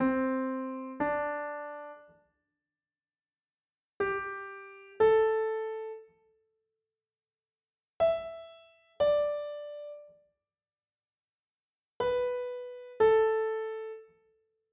For example here are several tones played one note after another:
Tones in a melody
melodic-tone-examples.mp3